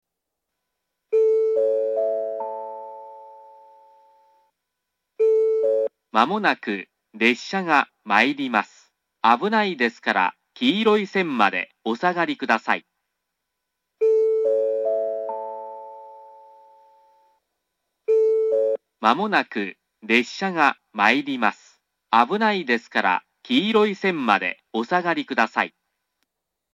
３番線接近放送